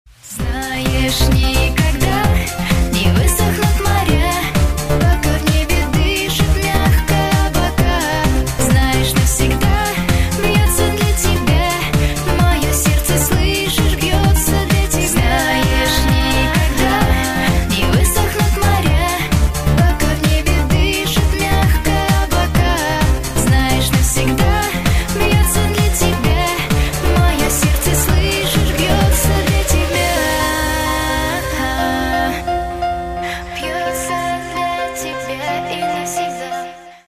• Качество: 128, Stereo
поп
громкие
женский вокал
dance
Electronic
электронная музыка